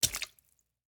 footstep-water.wav